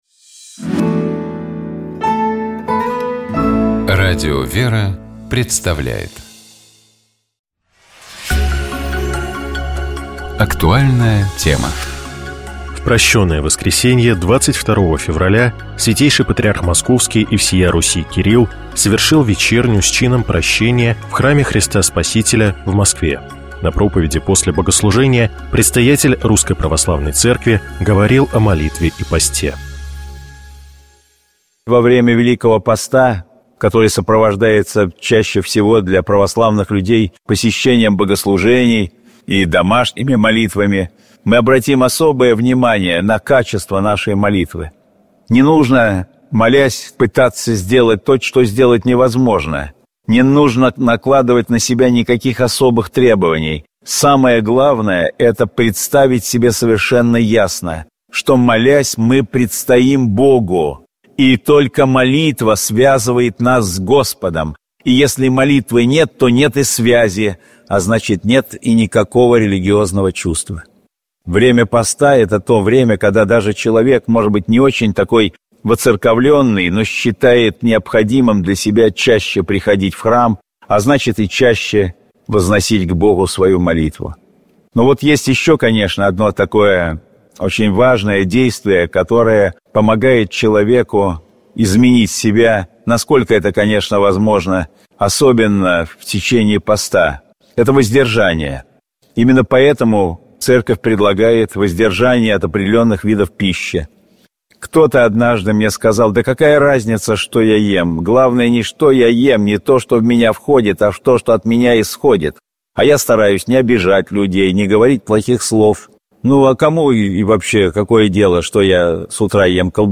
В Прощёное воскресение, 22 февраля, Святейший Патриарх Московский и всея Руси Кирилл совершил вечерню с чином прощения в Храме Христа Спасителя в Москве.
На проповеди после богослужения Предстоятель Русской Православной Церкви говорил о молитве и посте.